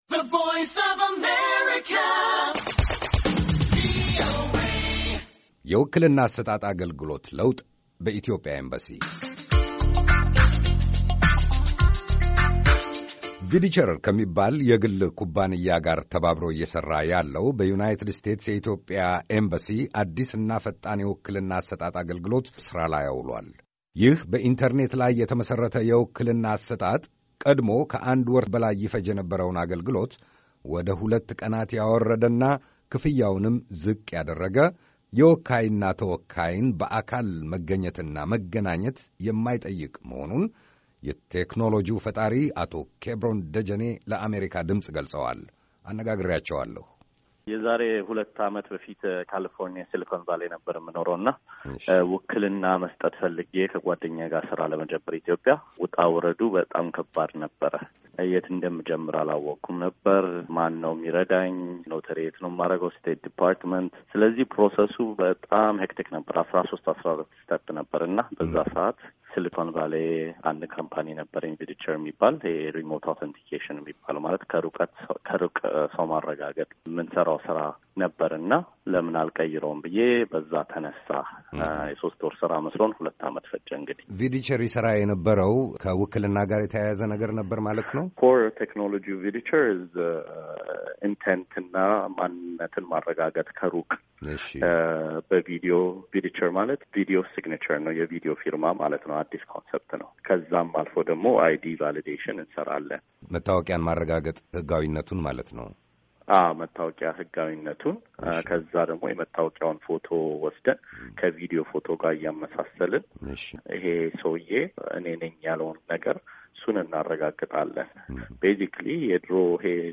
ቃለ-ምልልስ